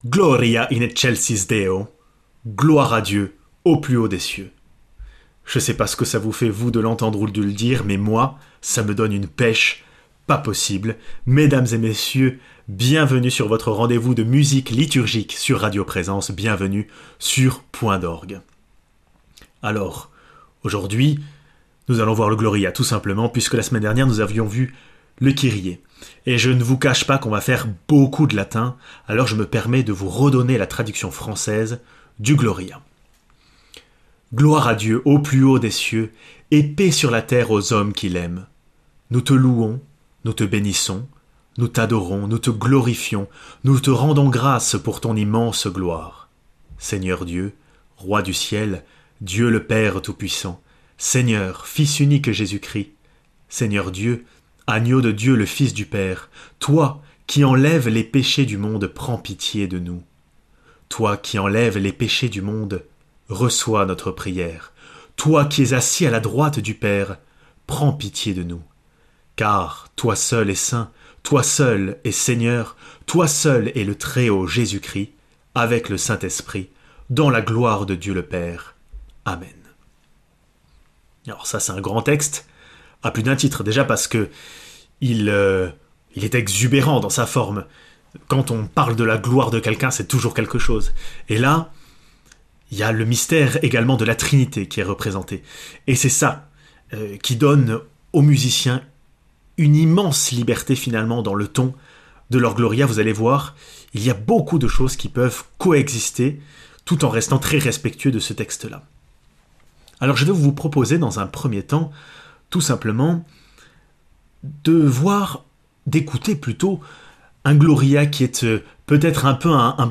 Musique